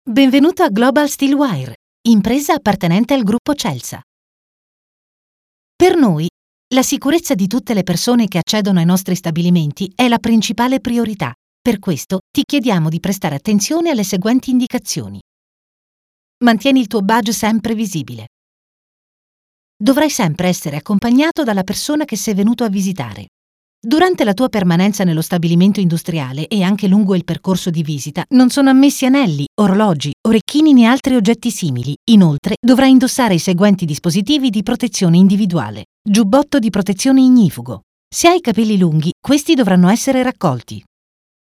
Naturelle, Polyvalente, Fiable, Mature, Douce
Corporate